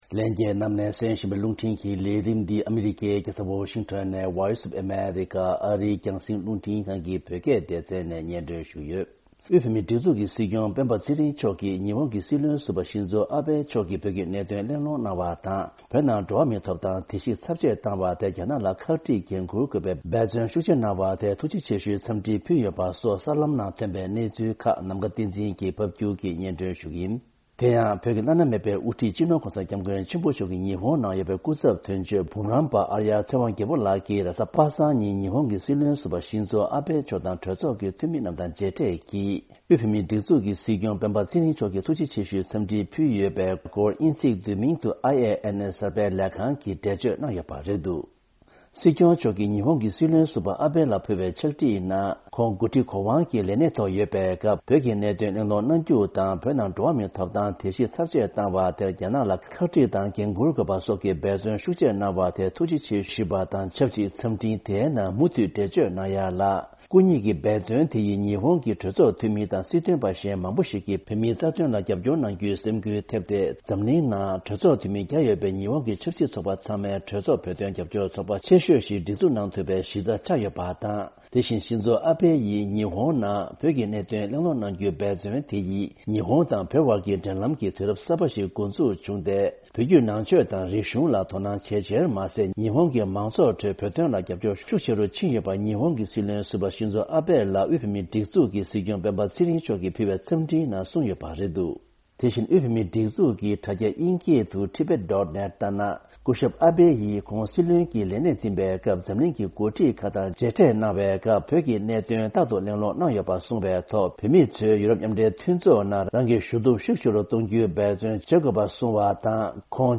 ཕབ་སྒྱུར་དང་སྙན་སྒྲོན་ཞུ་ཡི་རེད།